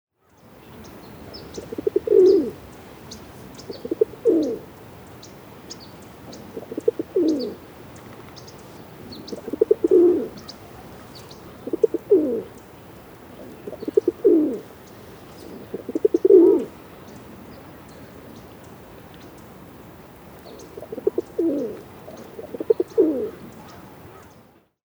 Звуки голубей
Взмах крыльев голубя — второй вариант